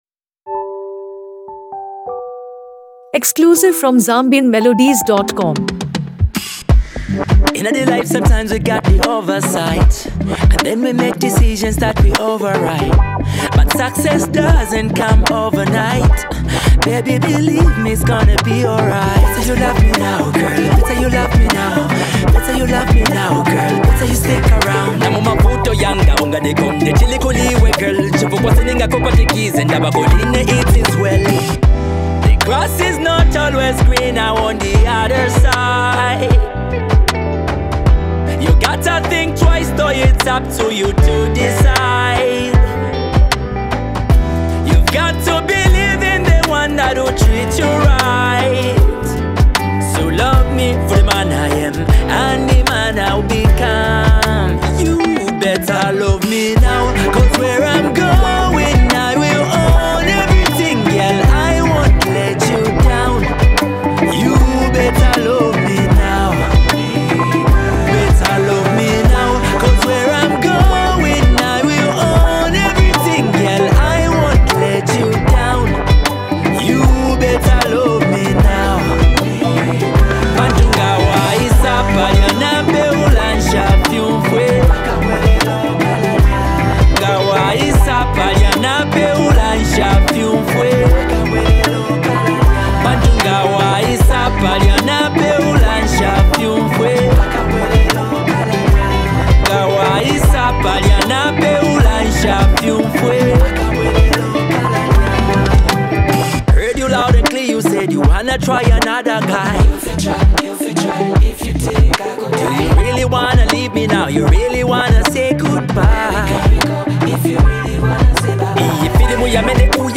A Soulful and Emotional Masterpiece
With a smooth blend of Afro-pop and R&B influences
soothing melody